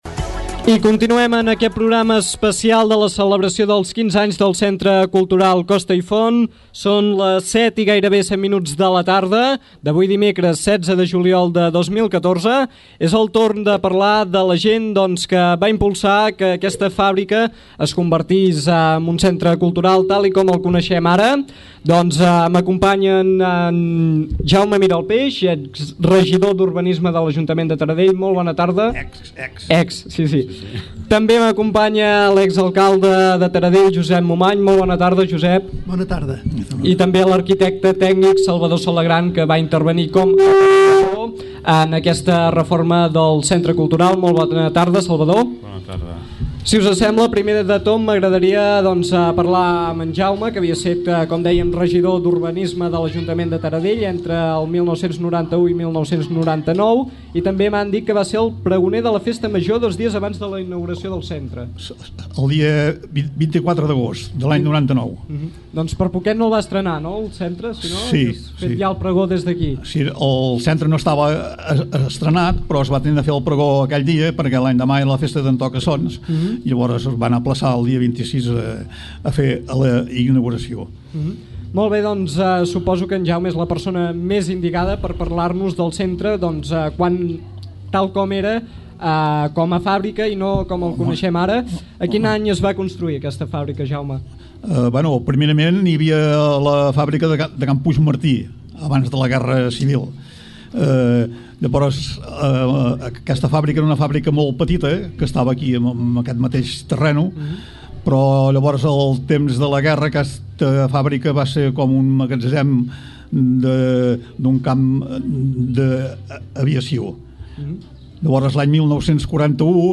Aquest dimecres i durant sis hores, Ràdio Taradell sortir al carrer. Concretament es va instal·lar als jardins del Centre Cultural Costa i Font entre les 5 de la tarda i les 11 de la nit per oferir un tastet de la seva programació en directe.